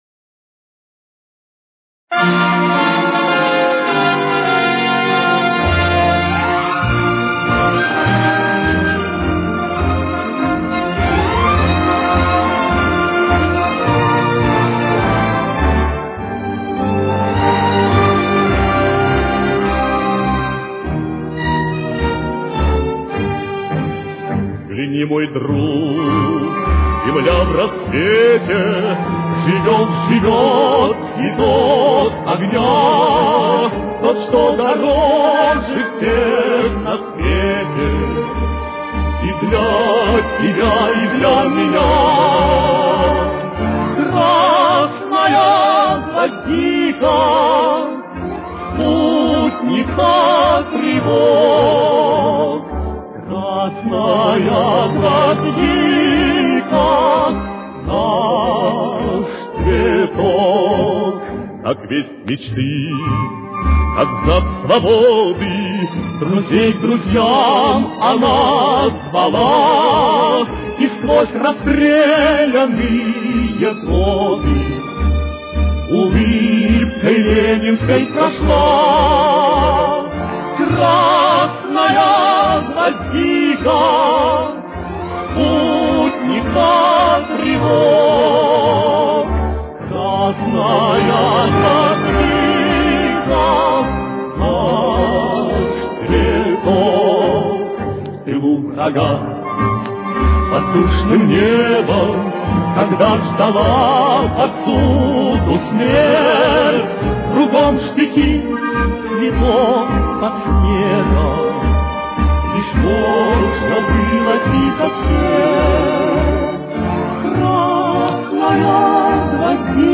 Темп: 105.